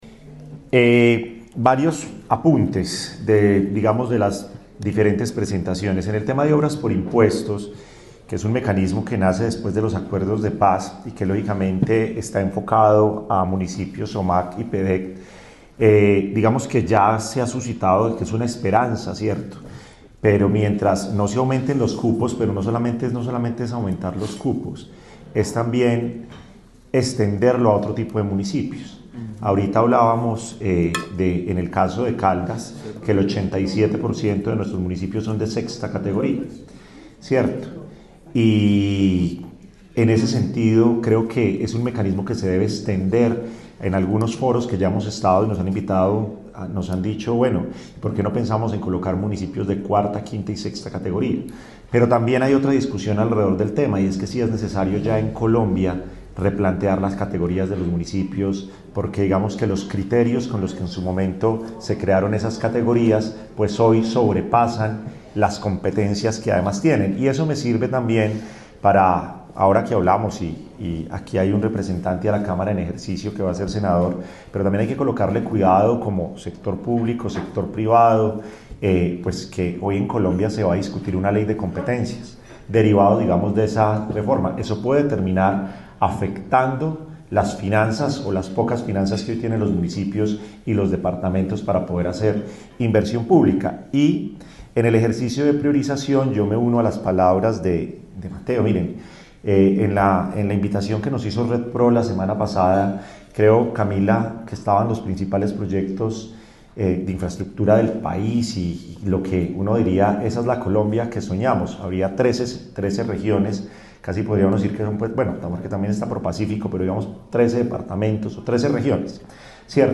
Con un mensaje de articulación y trabajo conjunto, la Secretaría de Planeación de la Gobernación de Caldas lideró el encuentro estratégico ‘Caldas Marca la Ruta: una visión de territorio para los próximos años’, un espacio que reunió a la institucionalidad, el sector privado, la academia y la bancada parlamentaria electa para construir una agenda común que proyecte al departamento en el escenario nacional.
Manuel Orlando Correa, Representante a la Cámara Electo.